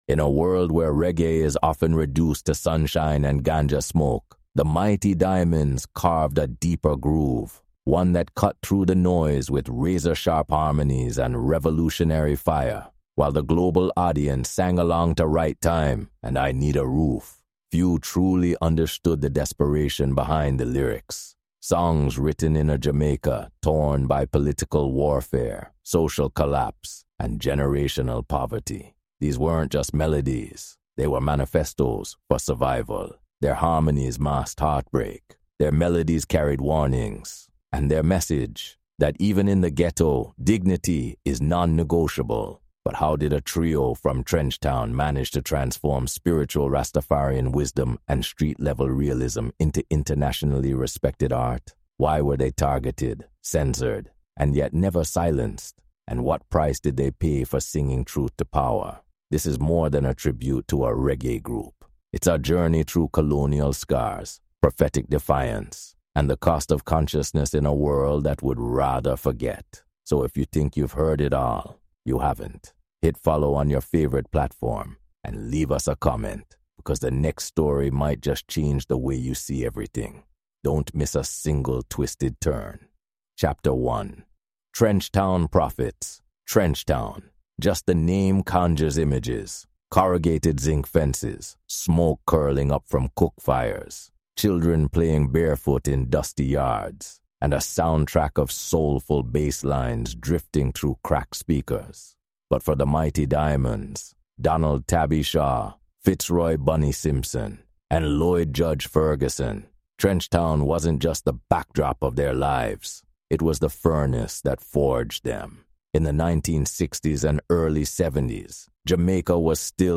The Mighty Diamonds – When Harmony Becomes a Weapon | Reggae & Caribbean History Documentary
The Mighty Diamonds – When Harmony Becomes a Weapon dives deep into Caribbean history and Jamaican music, tracing how three Trenchtown youths—Tabby, Bunny, and Judge—turned sweet harmonies into a fearless weapon against Babylon, colonization, and social injustice. Guided by leading history experts and reggae scholars, this episode weaves black history, heritage, and untold truths with rare studio tapes, live‑show memories, and eyewitness interviews from Kingston to Angola and Trinidad and Tobago